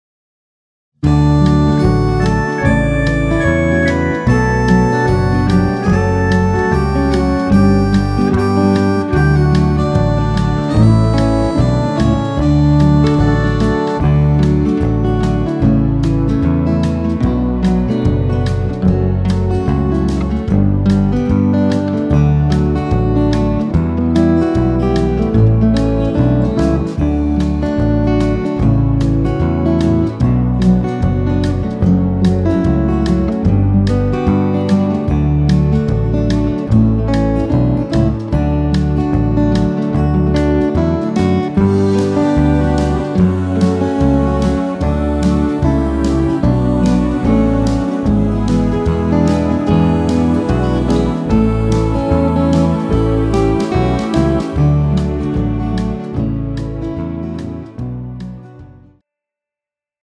Pop Swing style
tempo 148 bpm
key D
Male singers backing track